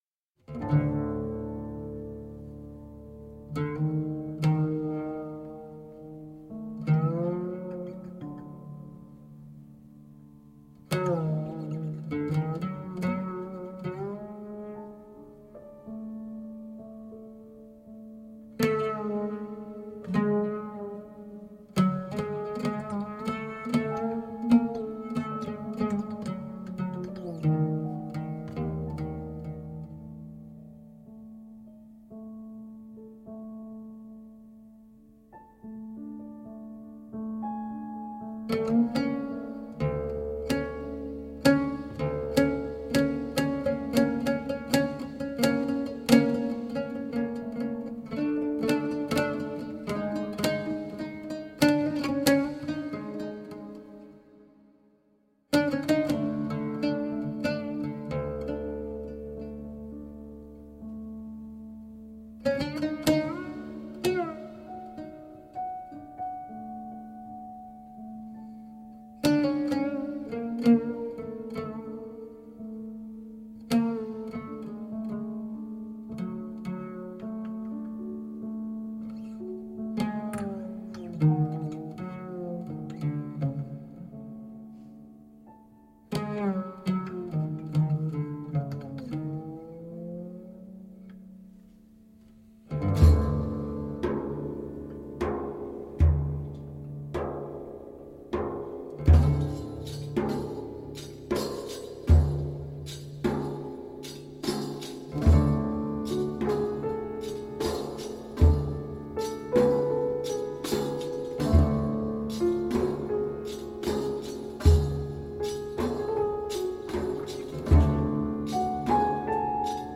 Middle eastern/balkan music and new age.
Oud, Bouzouki, saz, Flutes and Vocals
Piano, Accordion and Harmonium
Tabla, Drum set and Frame drums